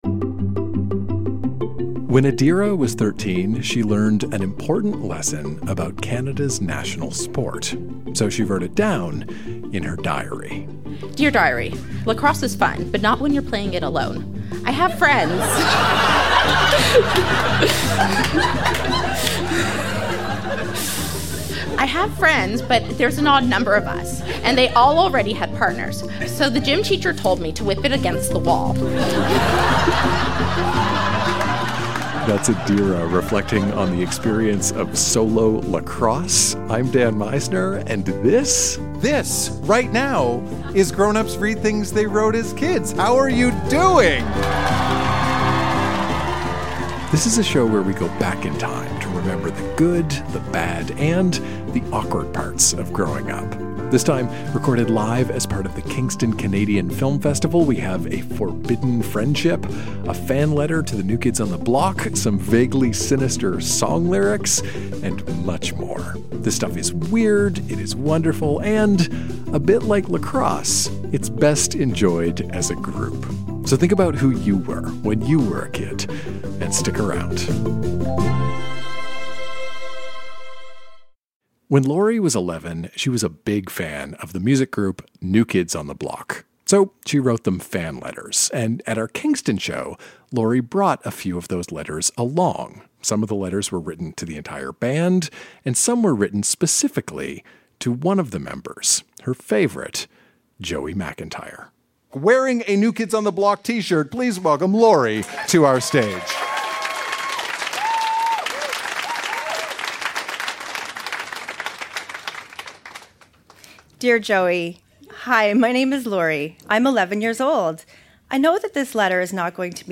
Recorded live at the Kingston Canadian Film Festival.